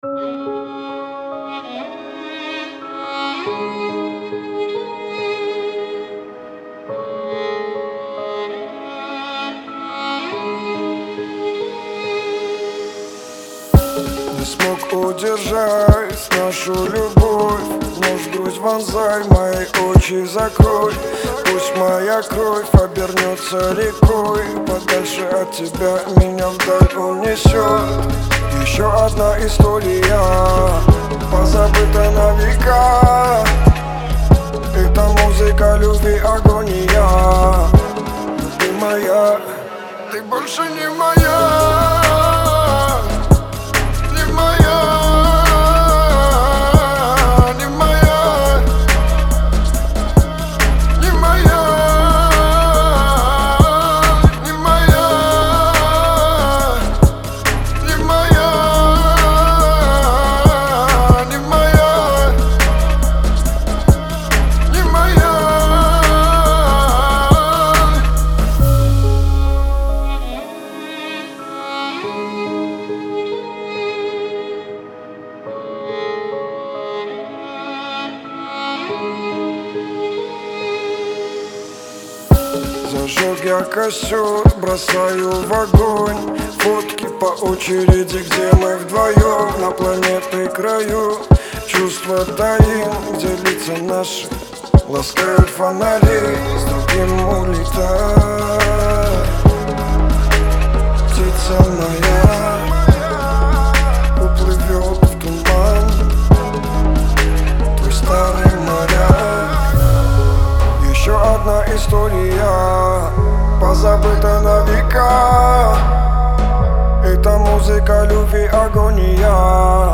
это эмоциональный трек в жанре поп с элементами R&B
мелодичное исполнение